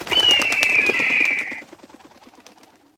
hawk.ogg